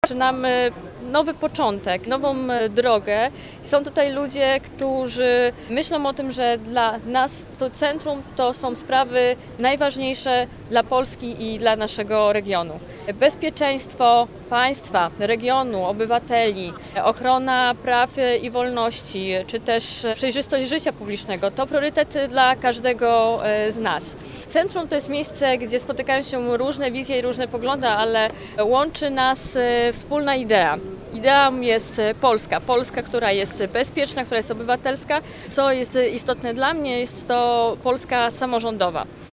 Centrum to dla Dolnoslazaków przestrzeń. by działać wspólnie, rozmawiać i wypracowywać dobre rozwiązania dla regionu i Polski – mówi Natalia Gołąb, członki Zarządu Województwa Dolnośląskiego.